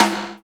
TOM RIM T1KL.wav